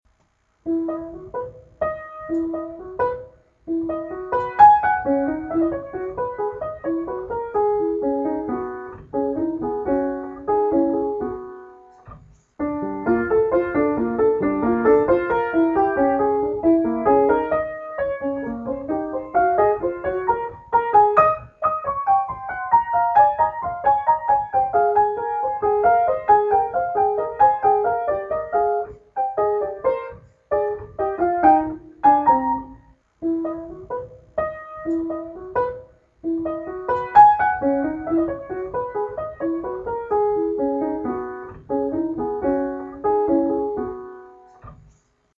Keyboard